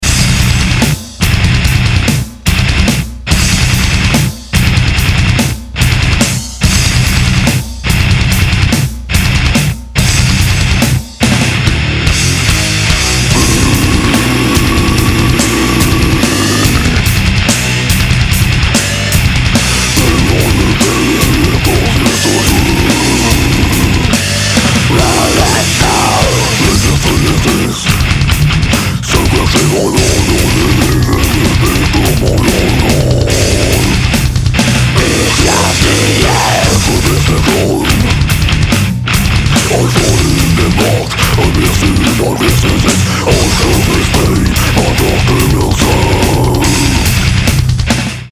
Nahrávací studio v Lipově audio / digital